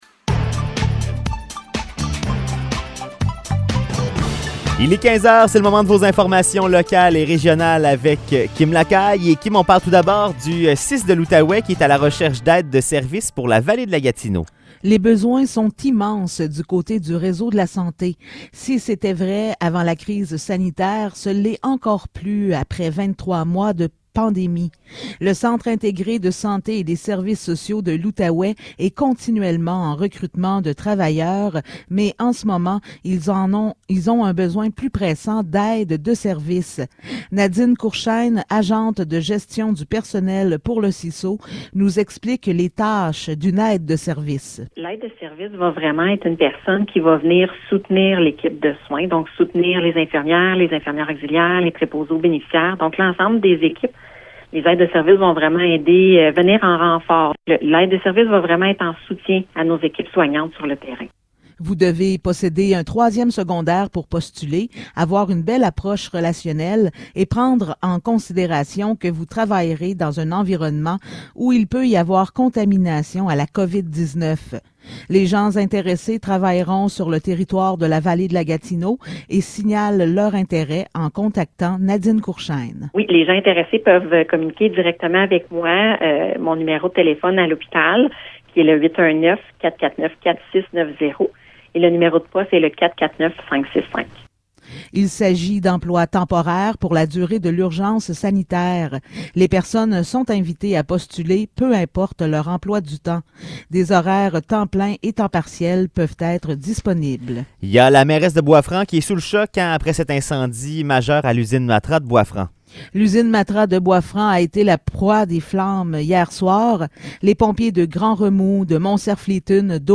Nouvelles locales - 28 janvier 2022 - 15 h